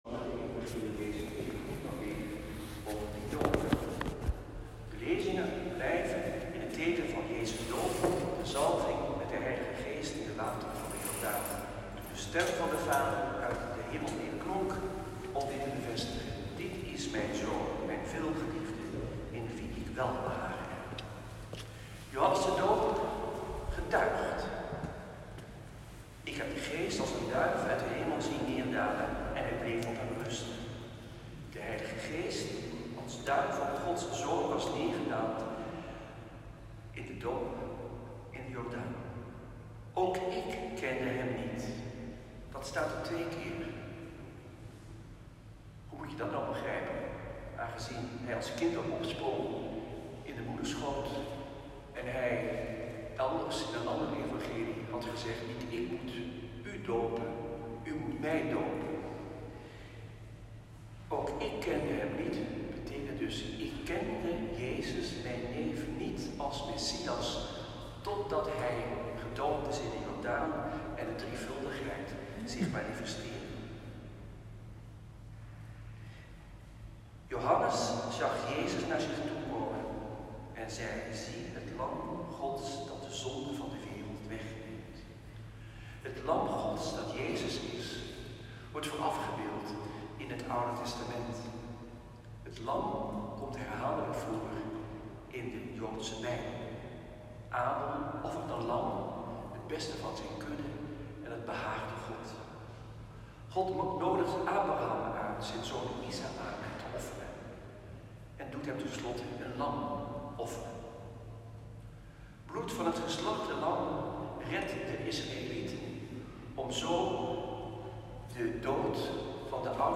Tweede zondag door het jaar A. Celebrant Antoine Bodar.
Preek-1.m4a